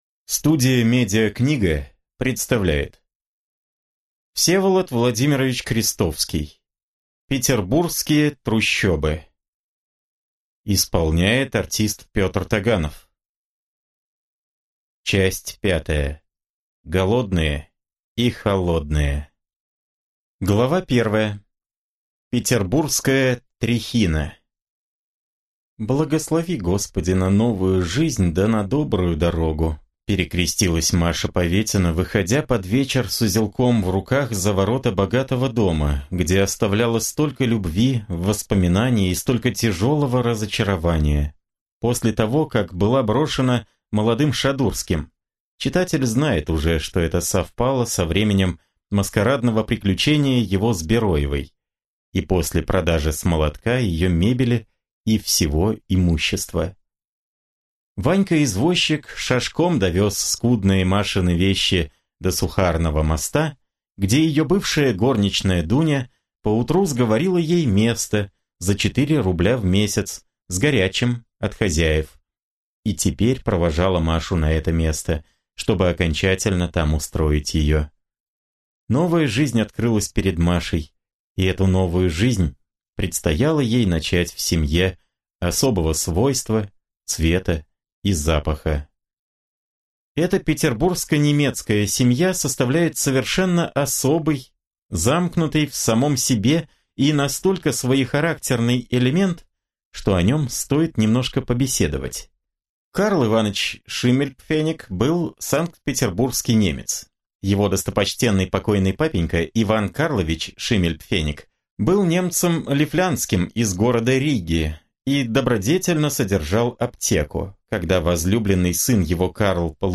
Аудиокнига Петербургские трущобы. Часть 5. Голодные и холодные | Библиотека аудиокниг